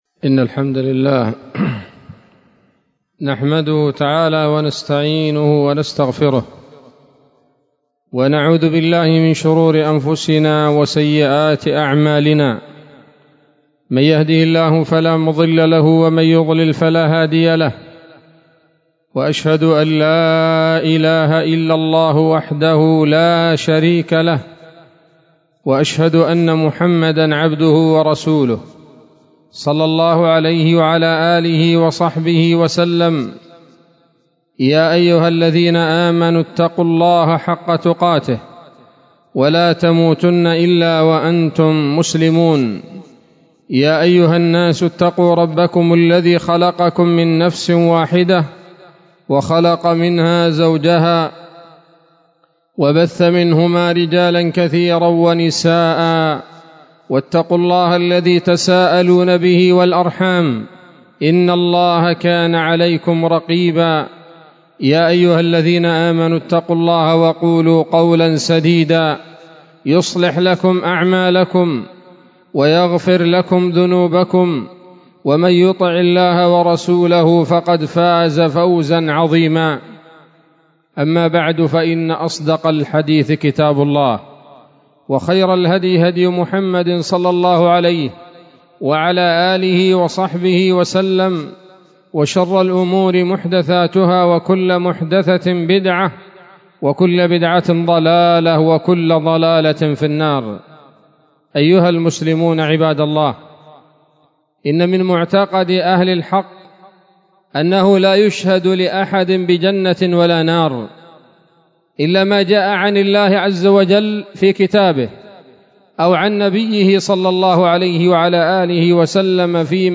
خطبة جمعة بعنوان
27 صفر 1444 هـ، دار الحديث السلفية بصلاح الدين